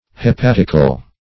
hepatical - definition of hepatical - synonyms, pronunciation, spelling from Free Dictionary Search Result for " hepatical" : The Collaborative International Dictionary of English v.0.48: Hepatical \He*pat"ic*al\, a. Hepatic.
hepatical.mp3